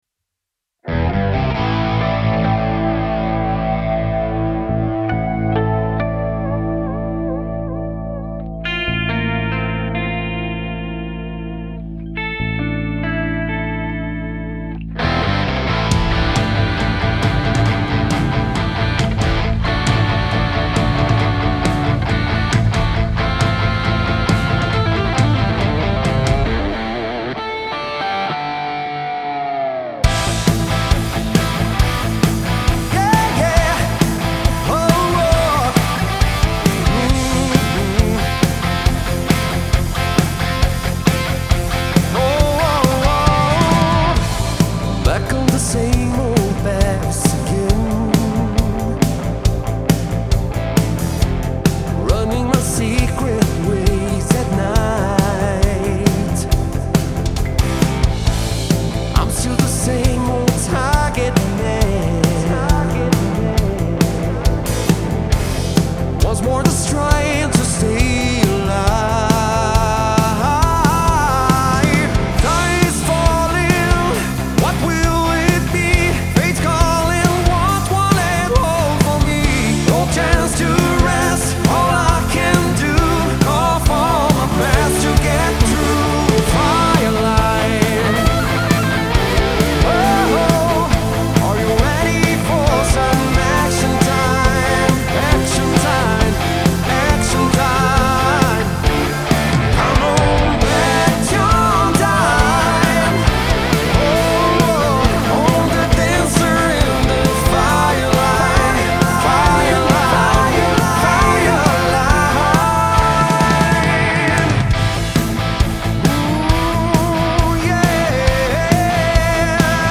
Genre: Synth-Rock